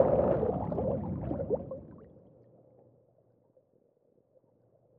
Drown.wav